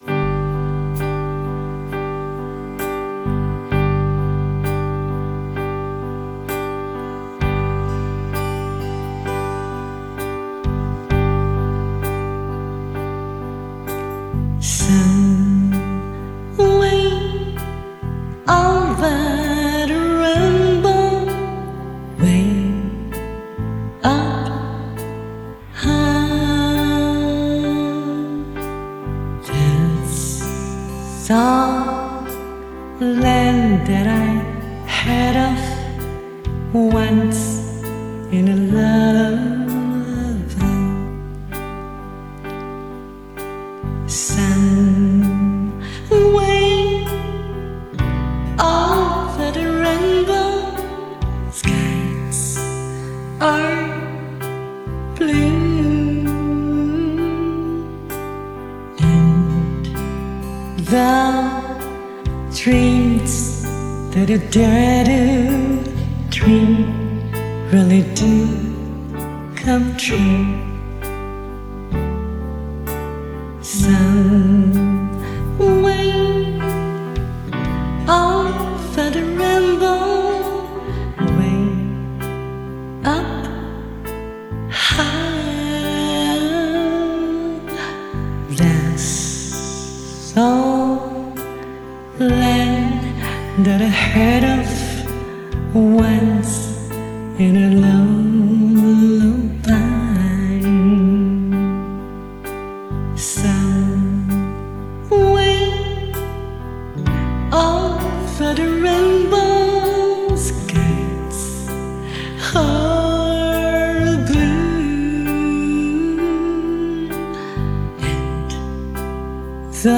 弹唱